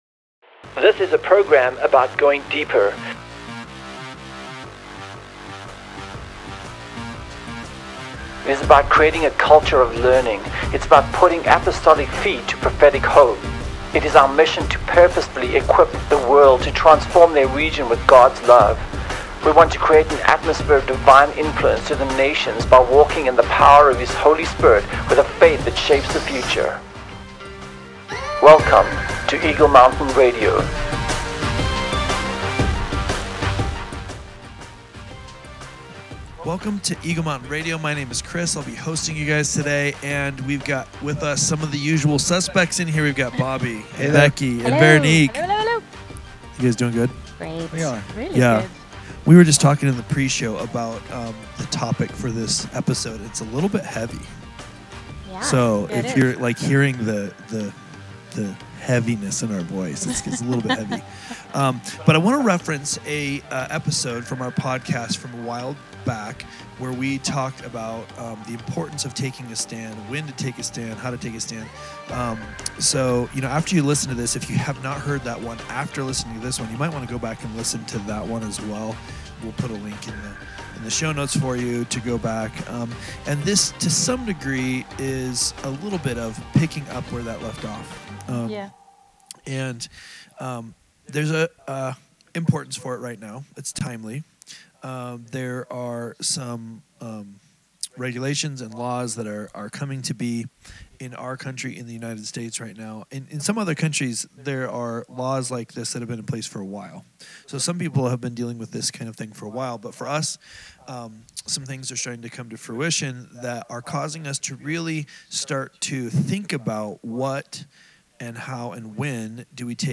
How are we equally responsible for the change we want to see in the world? Let’s go deep with our panel as we discuss these heavy topics.